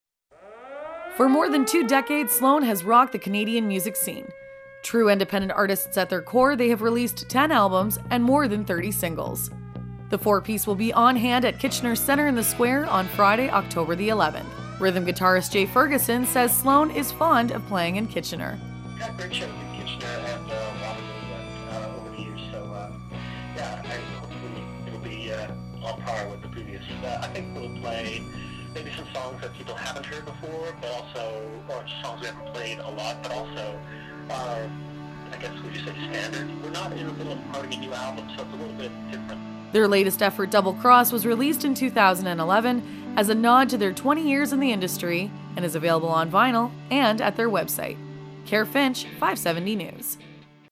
I spoke with Jay Ferguson (rhythm guitar) about Sloan returning to Kitchener (as he jokingly says for the “fortieth time”).